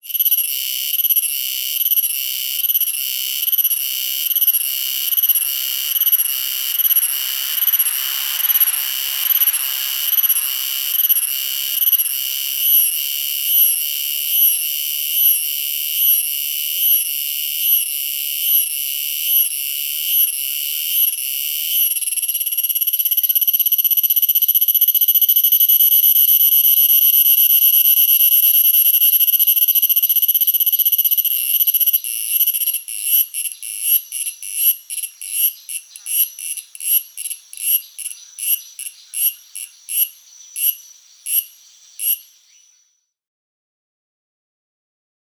Species: Purana ptorti